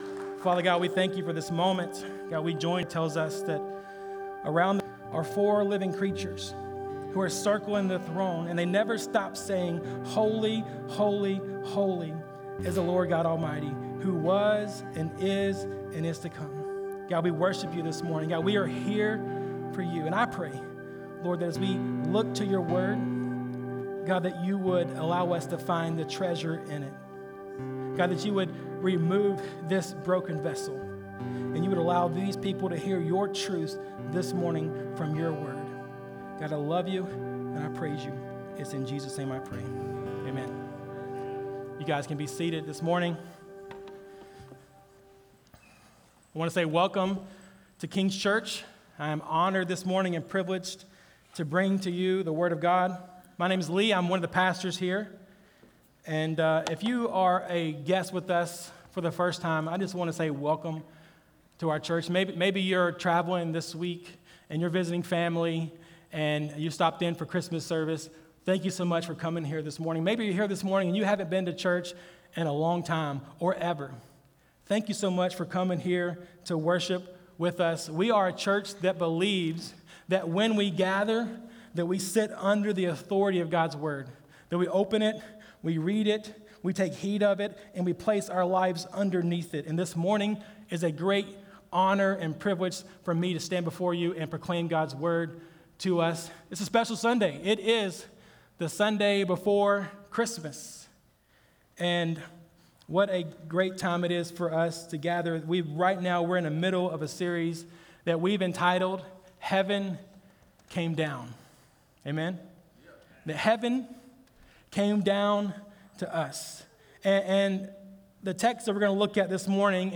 Dec22Sermon.mp3